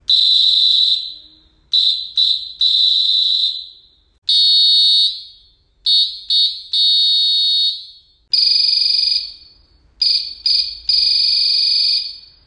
電子ホイッスル [TOEI LIGHT]
3種類の合図音で使い分けが可能！